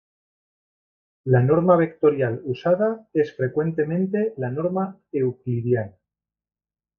Read more Noun Verb normar to norm to normalize; to standardize Read more Frequency C1 Hyphenated as nor‧ma Pronounced as (IPA) /ˈnoɾma/ Etymology From Latin nōrma.